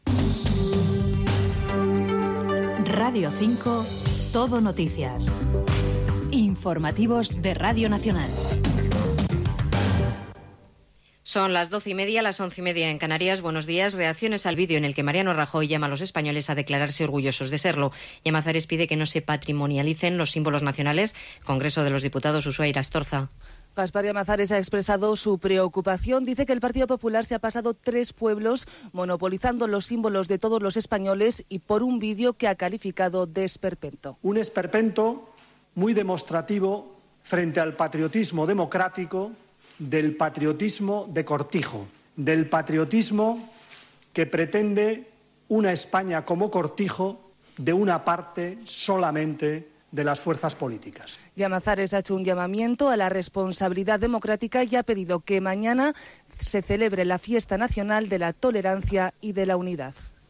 Indicatiu de la ràdio, hora i reaccions al vídeo patriòtic del Partido Popular amb declaració del polític Gaspar Llamazares de Izquierda Unida
Informatiu